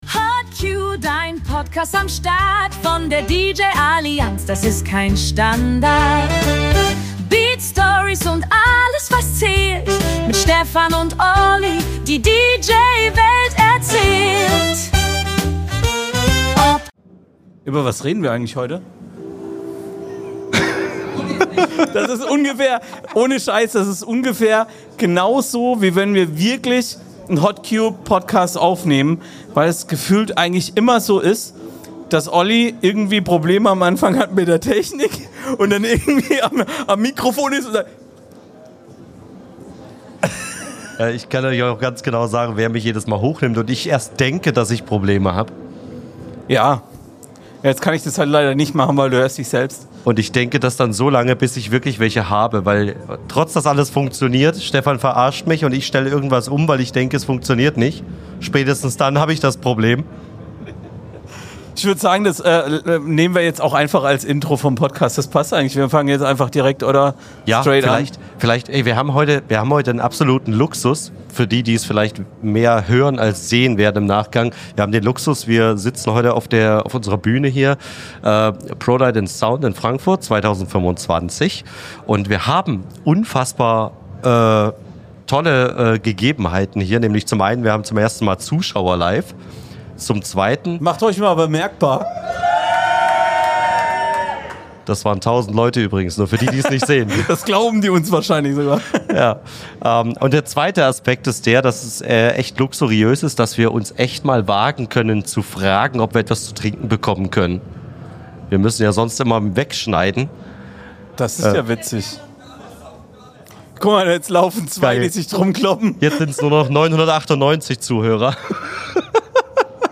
LIVE von der Prolight & Sound ~ HOTCUE Podcast